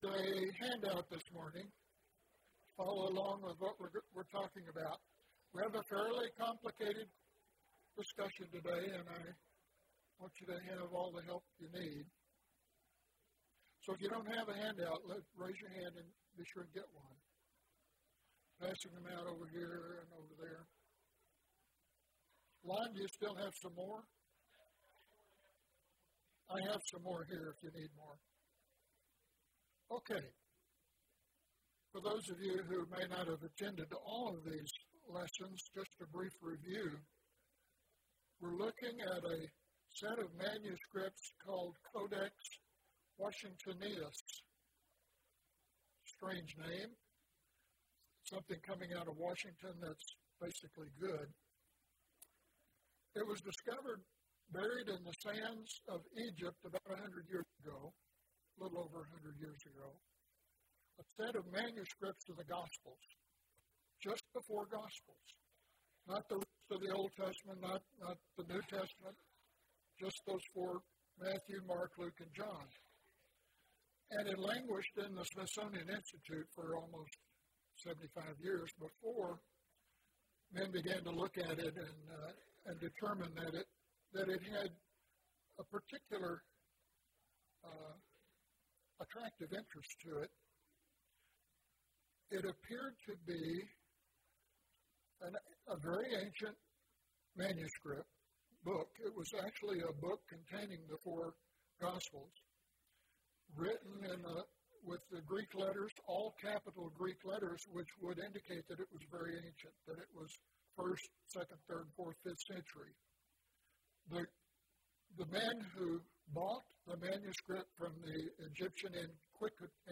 The Gospel of John and a Revealing Error (11 of 14) – Bible Lesson Recording